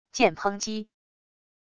剑抨击wav音频